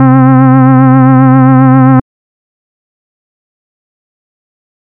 Organ (5).wav